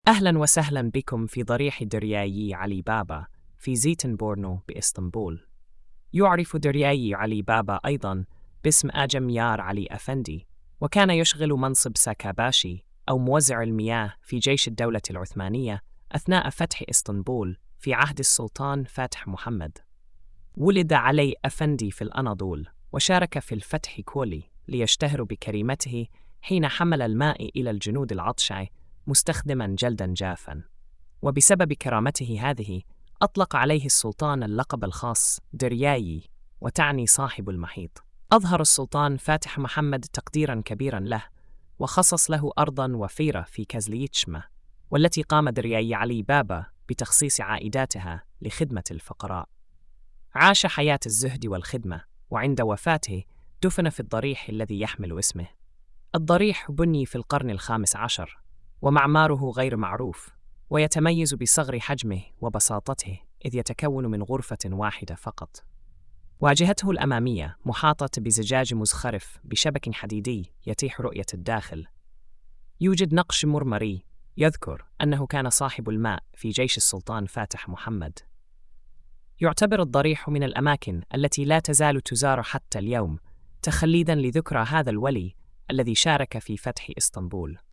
السرد الصوتي: